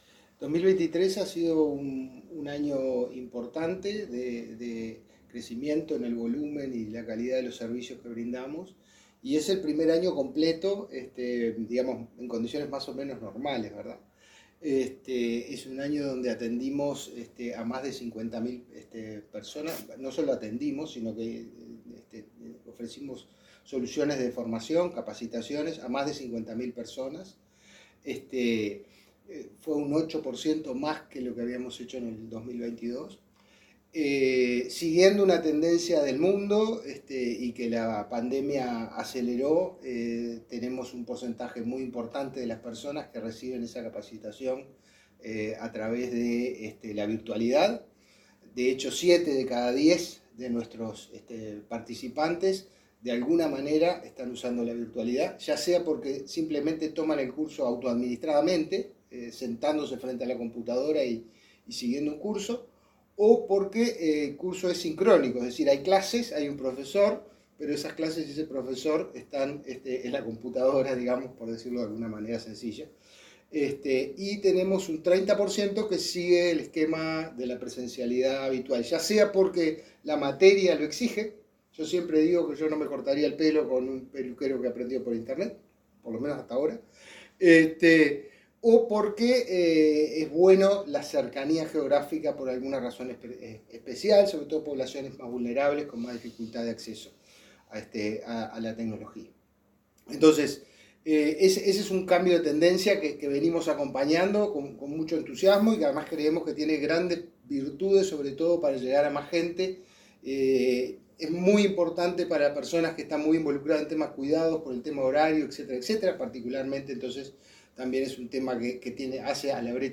Entrevista a director general de Inefop, Pablo Darscht